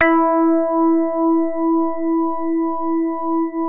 JAZZ MID  E3.wav